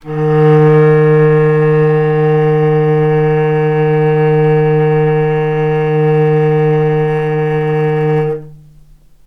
vc-D#3-mf.AIF